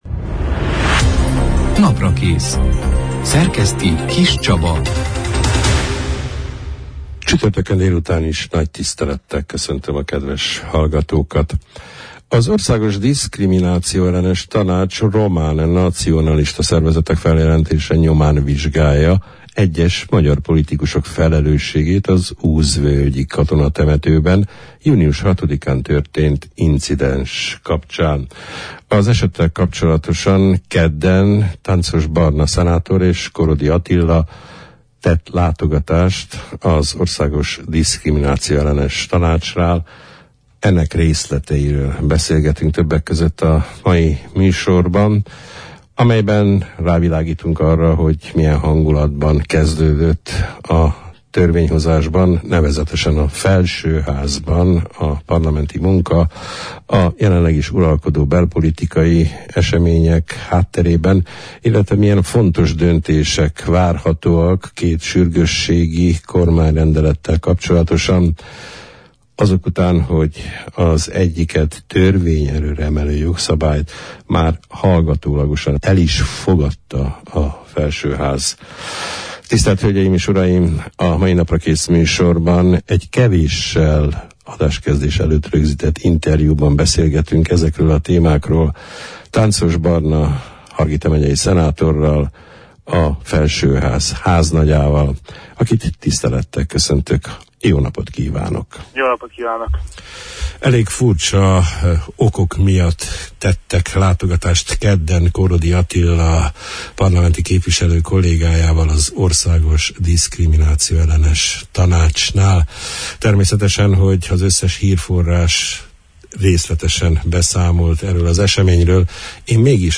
Az úzvölgyi történések nyomán, román nacionalista szervezetek feljelentése miatt volt az Országos Diszkriminációellenes Tanácshoz behívva, magyar politikusok egy csoportja. A meghallgatás tapasztalatairól, az őszi parlamenti űlésszak kezdeti hangulatáról, a politikai iszapbirkózás törvényhozási aktualitásairól, két fontos sürgősségi kormányrendelet parlamenti vitájának várható kimeneteléről beszélgettünk, a szeptember 5 -én, csütörtökön elhangzott Naprakész műsorban Tánczos Barna szenátorral, a felsőház háznagyával.